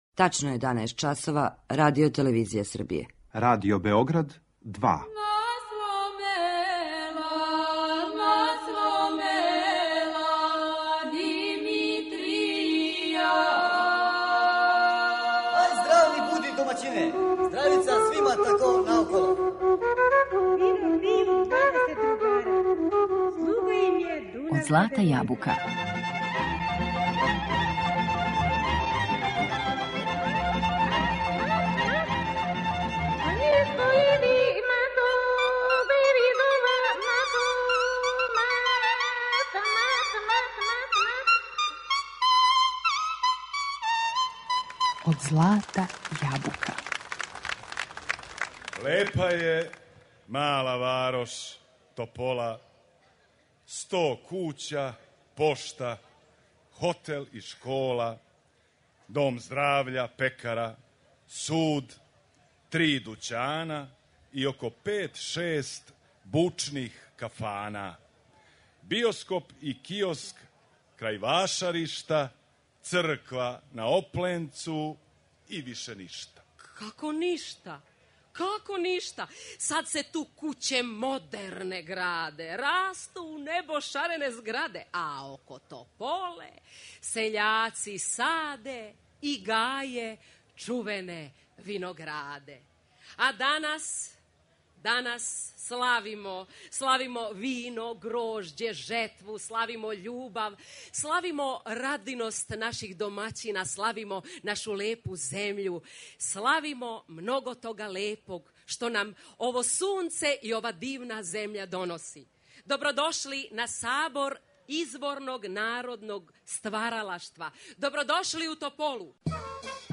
Кроз различите примере вокалног и инструменталног извођења представљене су основне карактеристике музичког наслеђа овога краја Србије.